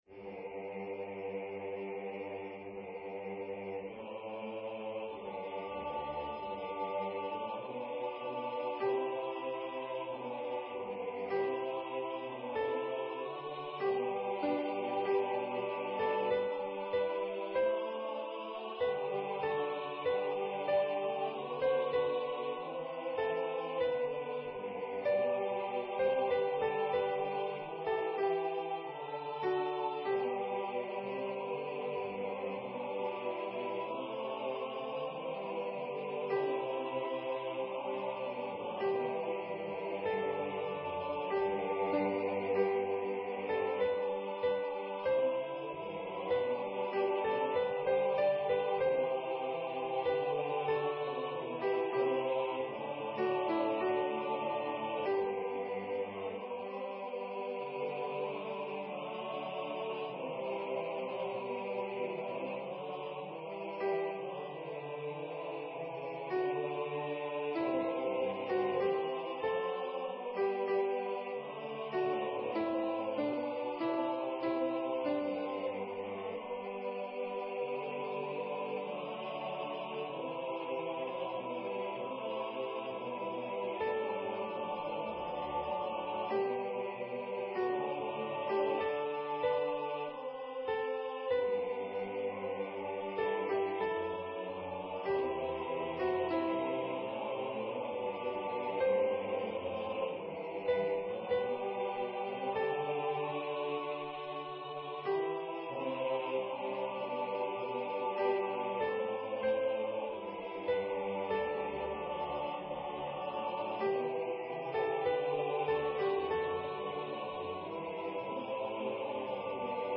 with Accompaniment
MP3 Practice Files: Soprano:
SicutCervusPalestrinaSoprP.mp3